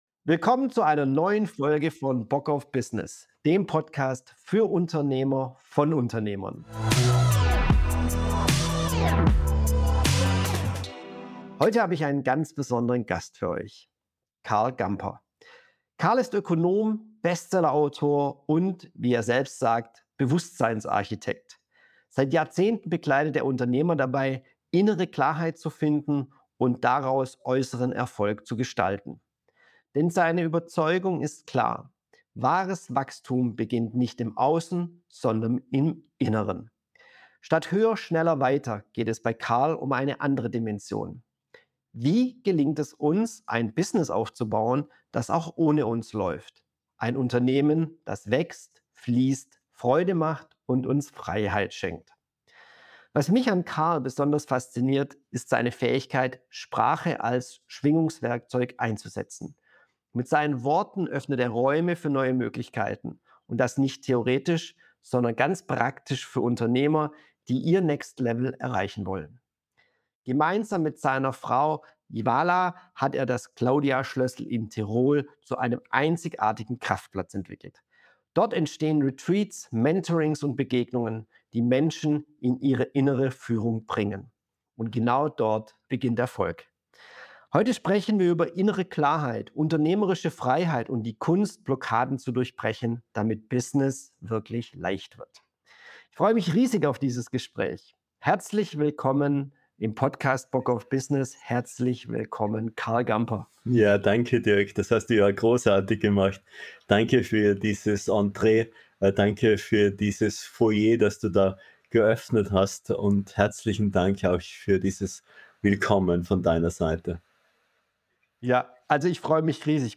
Mit seinen Worten öffnet er Räume für neue Möglichkeiten und genau darum geht es in diesem Gespräch.